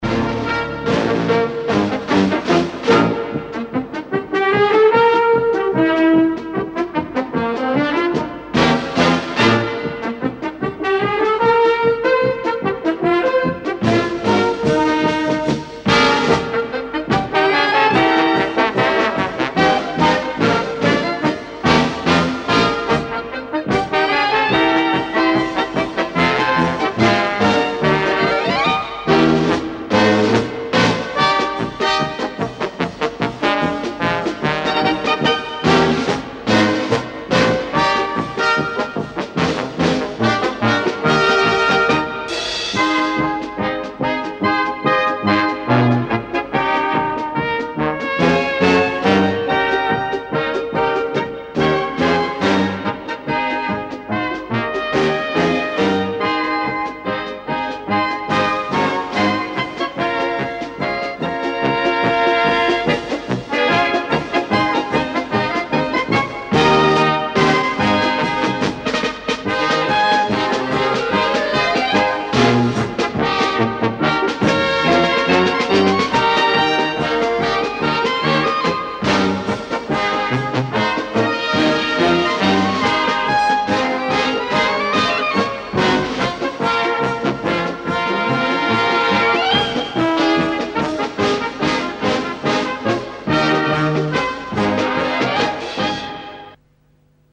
инструментальная пьеса